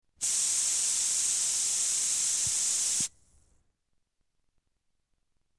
sillystring.wav